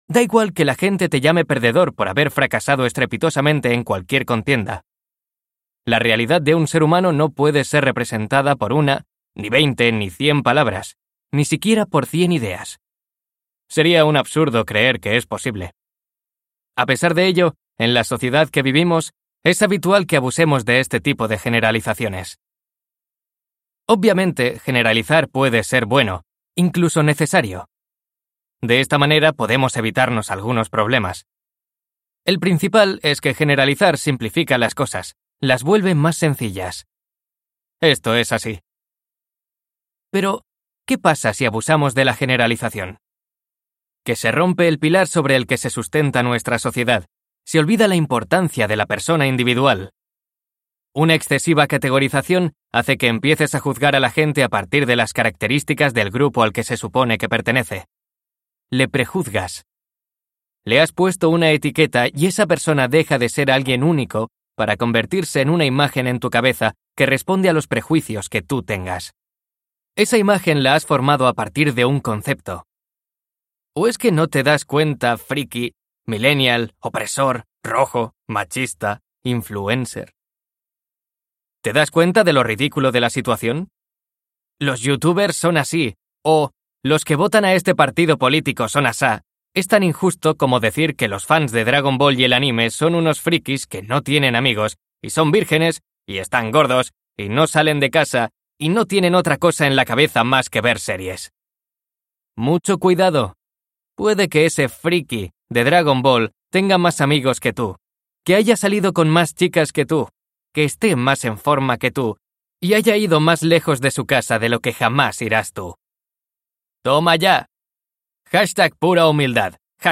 TIPO: Audiolibro
ESTUDIO: Eclair Barcelona